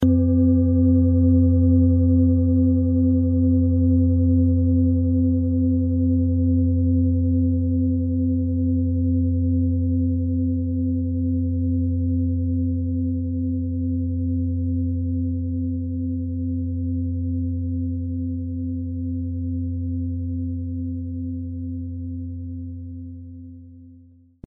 Von Hand getriebene Klangschale mit dem Planetenklang Sonne aus einer kleinen traditionellen Manufaktur.
• Höchster Ton: Lilith
Um den Originalton der Schale anzuhören, gehen Sie bitte zu unserer Klangaufnahme unter dem Produktbild.
PlanetentonSonne & Lilith (Höchster Ton)
MaterialBronze